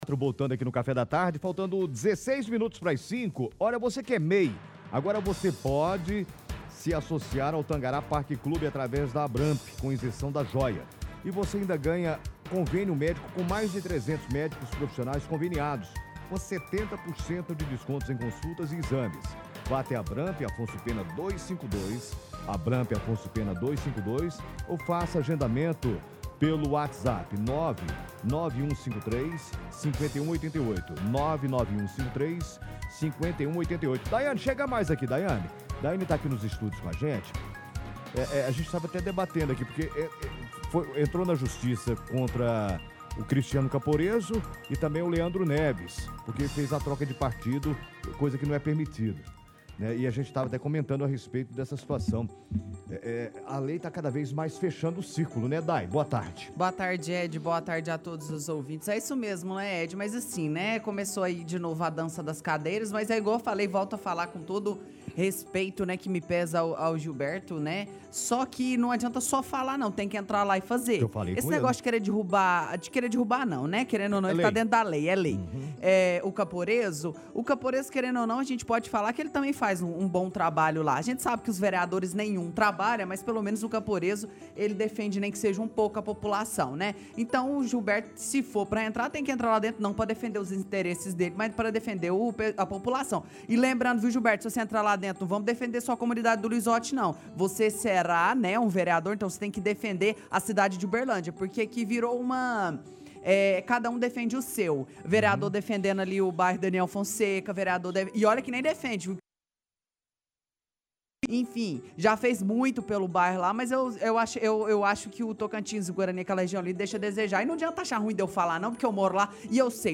Bate-papo.mp3